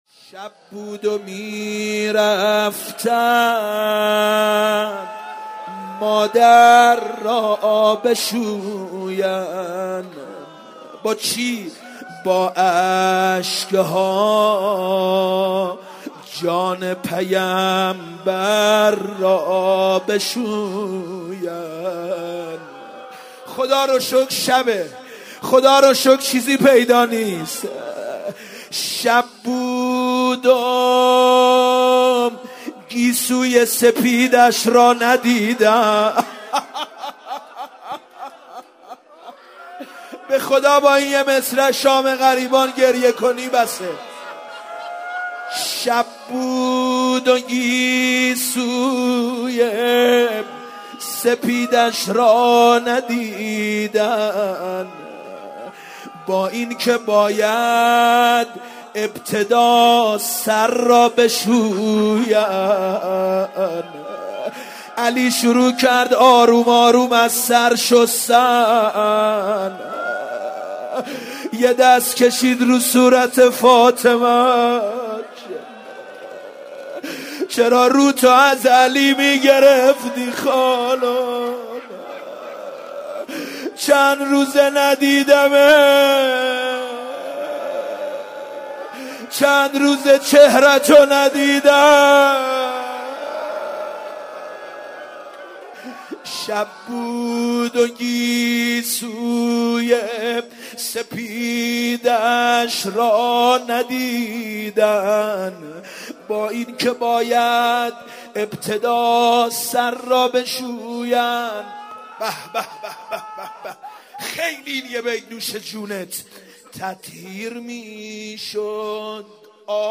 مرثیه خوانی